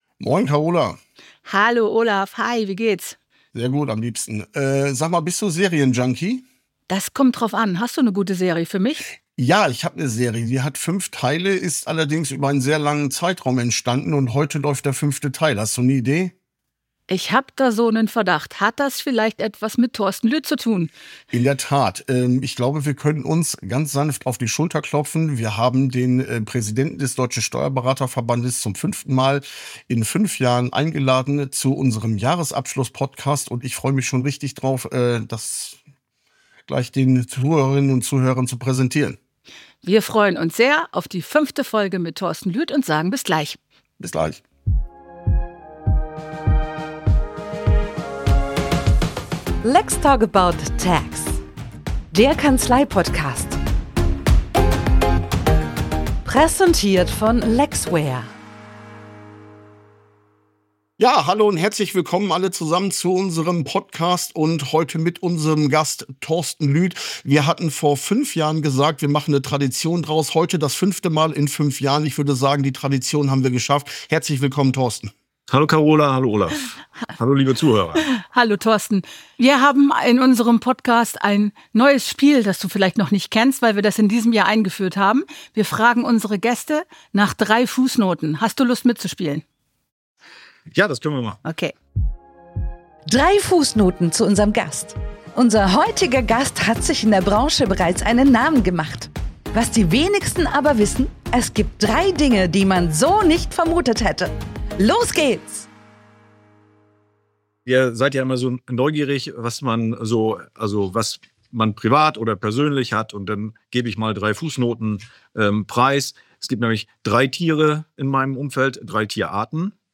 Ein mitreißendes offenes Gespräch über die aktuelle Lage, zentrale Herausforderungen und notwendige Veränderungen in der Steuerberatung.